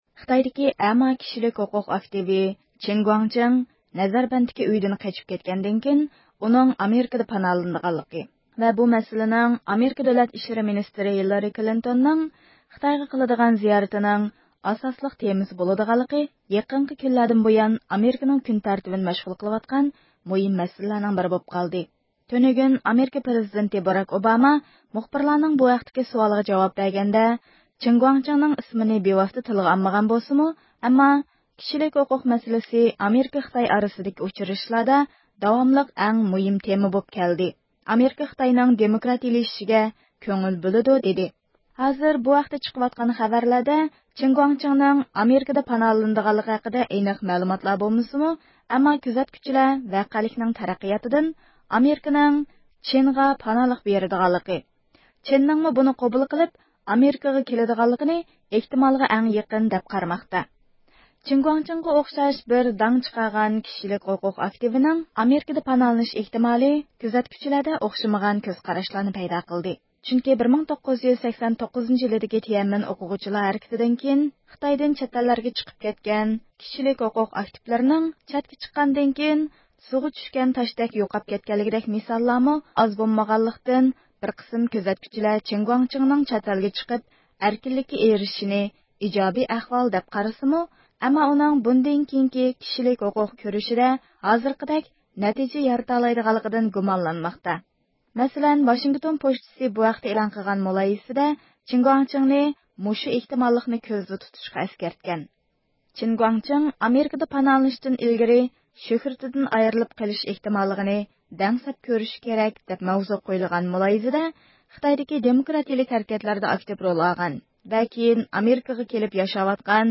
رابىيە قادىر خانىم بۈگۈن بۇ ھەقتە رادىئومىزغا بايانات بېرىپ، ئۆزىنىڭ نەتىجە قازىنىشىدىكى سەۋەبلەر ئۈستىدە توختالدى.
رابىيە قادىر خانىم بۈگۈن رادىئويىمىزنىڭ زىيارىتىنى قوبۇل قىلىپ، ئۆزىنىڭ بۇ ھەقتىكى تەسىراتلىرىنى بىز بىلەن ئورتاقلاشتى.